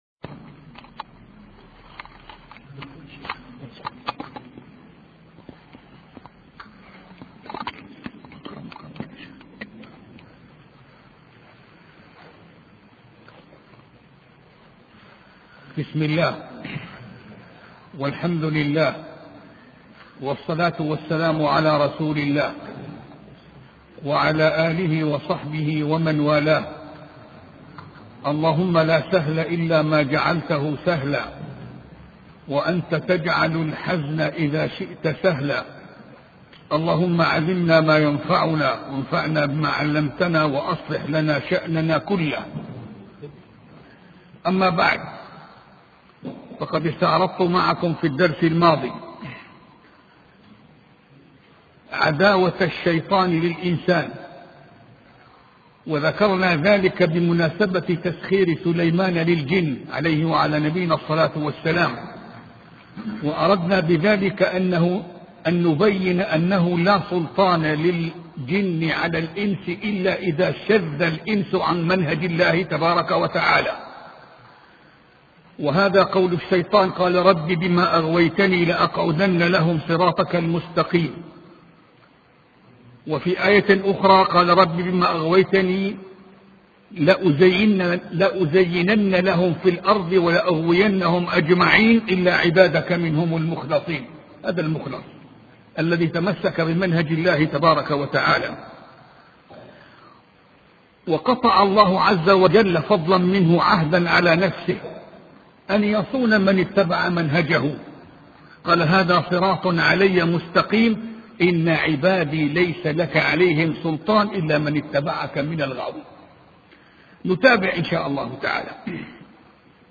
سلسلة محاضرات في قصة سليمان علية السلام